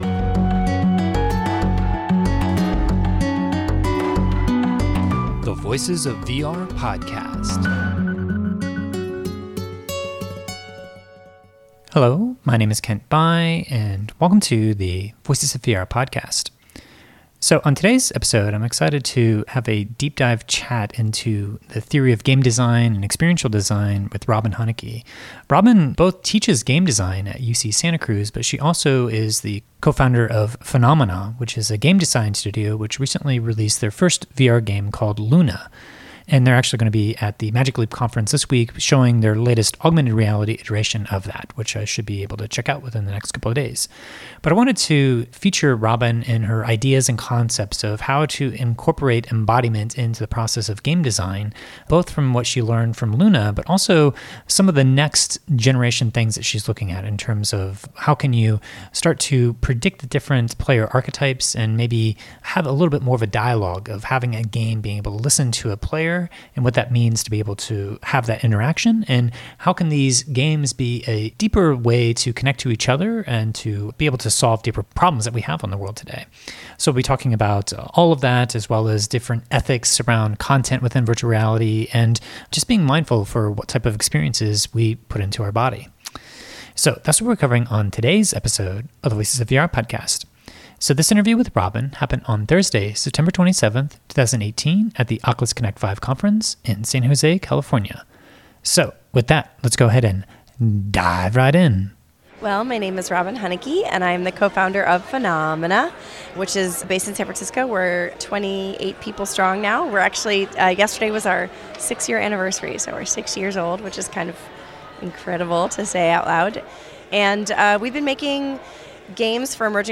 I had a chance to catch up with Hunicke at Oculus Connect 5 where she shared her insights into embodied game design, game design theory, experiential design, and some of the things that she’s working on next in terms of how to get a game to listen and better respond to player behaviors based upon a set of different player archetypes.